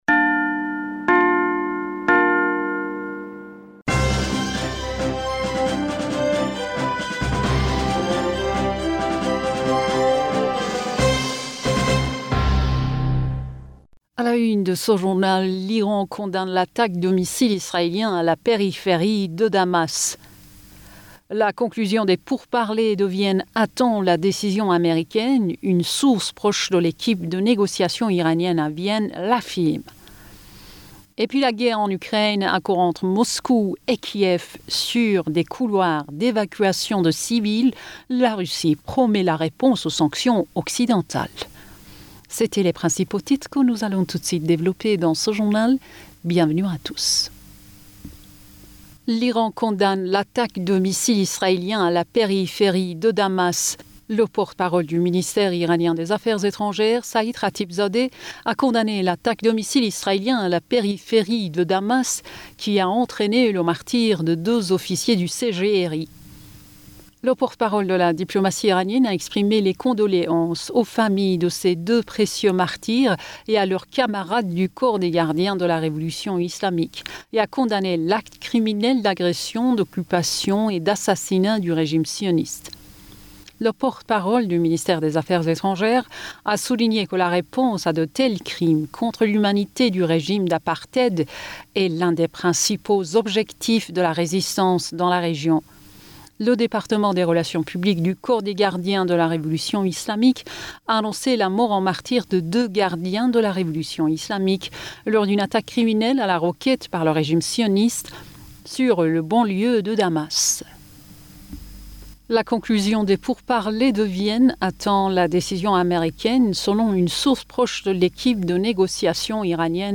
Bulletin d'information Du 09 Mars 2022